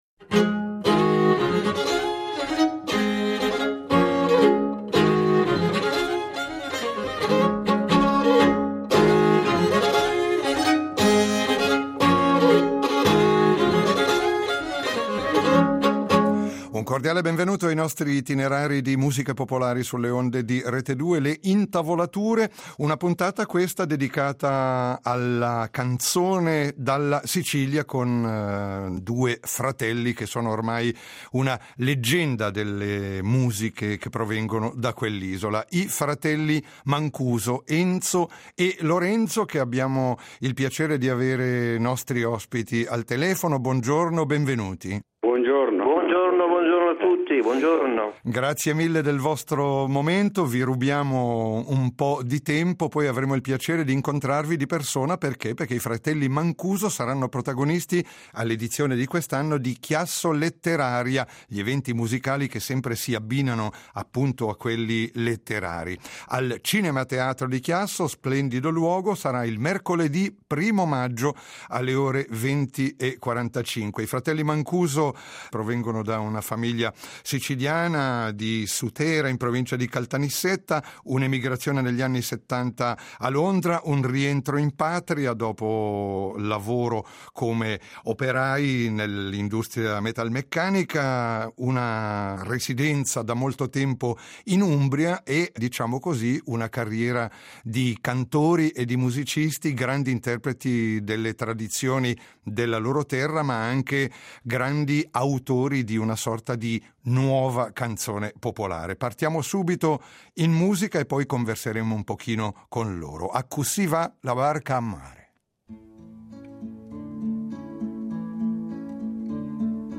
La nostra trasmissione folk ospita questa settimana i Fratelli Mancuso , leggendari ambasciatori della musica della loro terra, la Sicilia, dalle radici più tradizionali alla nuova canzone d’autore. Enzo e Lorenzo Mancuso hanno saputo interpretare con grandissima sensibilità ed espressività tematiche che riguardano l’uomo nel suo profondo, con testi molto poetici, con le loro voci forti e commoventi al tempo stesso, con ricercatezza della veste sonora delle loro canzoni, sempre di grande intensità e delicatezza…